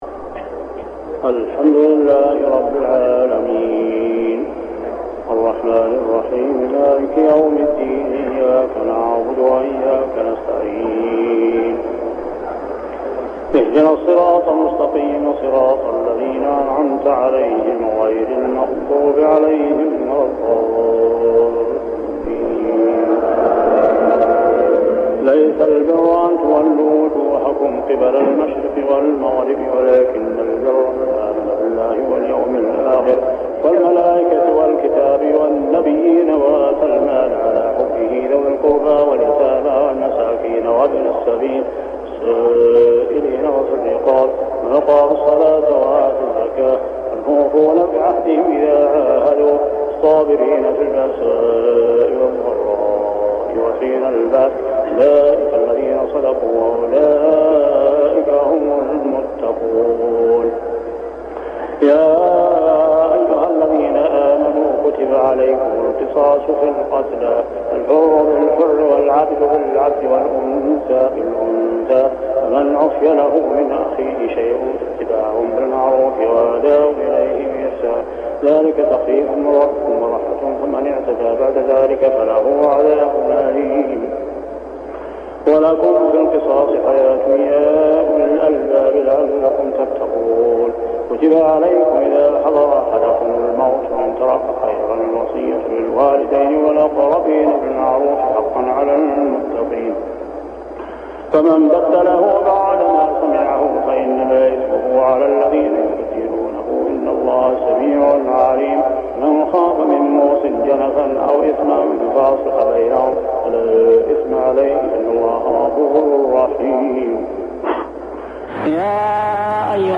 صلاة التهجد عام 1399هـ من سورة البقرة 177-202 مع الدعاء | Tahajjed prayer surah Al-Baqarah with Dua > تراويح الحرم المكي عام 1399 🕋 > التراويح - تلاوات الحرمين